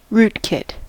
rootkit: Wikimedia Commons US English Pronunciations
En-us-rootkit.WAV